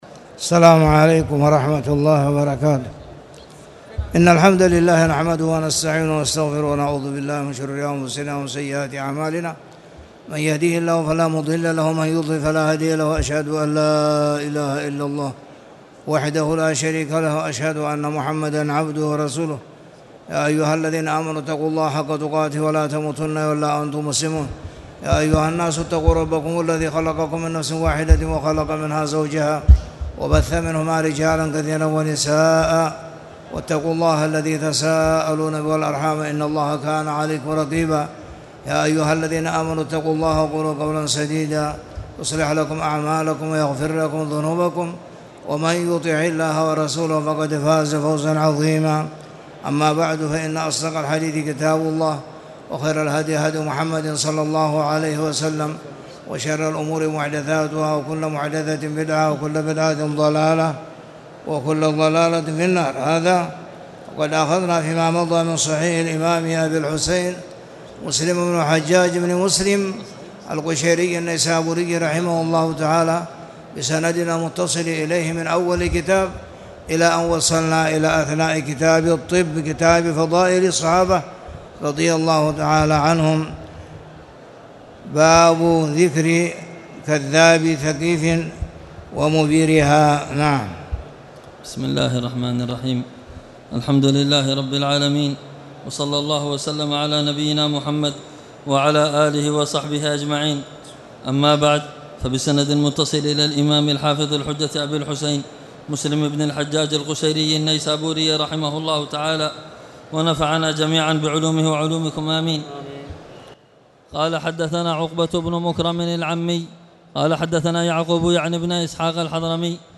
تاريخ النشر ٤ ربيع الثاني ١٤٣٨ هـ المكان: المسجد الحرام الشيخ